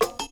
Closed Hats
Wu-RZA-Hat 22.WAV